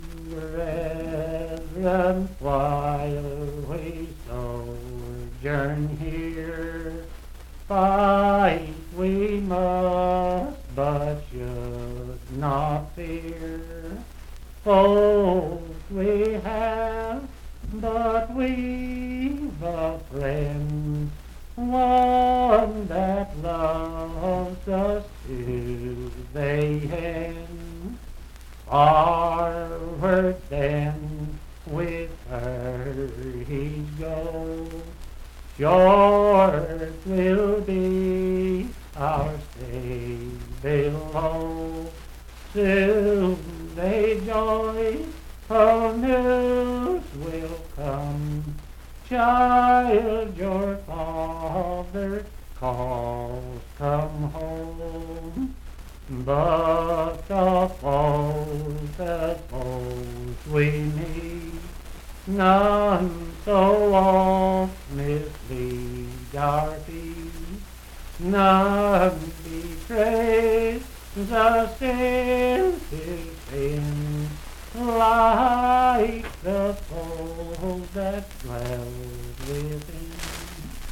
Unaccompanied vocal music
in Dryfork, WV
Hymns and Spiritual Music
Voice (sung)
Randolph County (W. Va.)